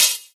Hat (15).wav